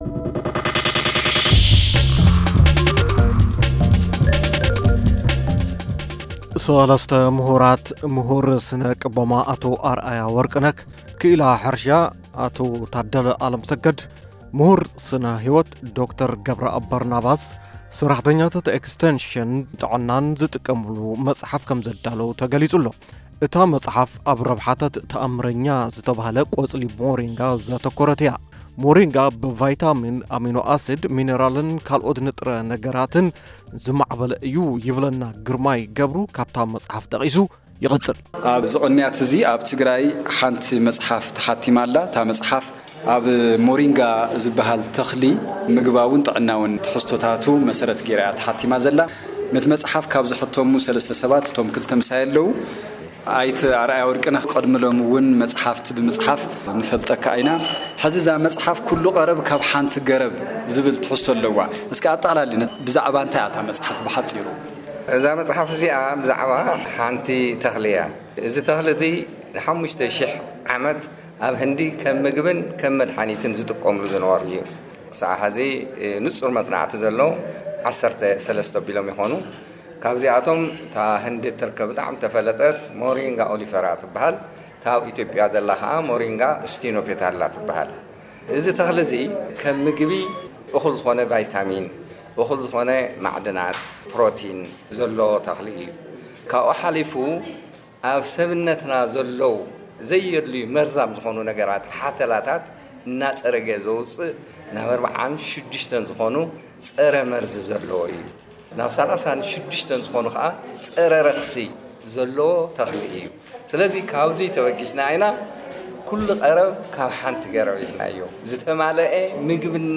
ቃለ-ምልልስ ኣብ ልዕሊ ሞሪንጋ ኦሊፌይራ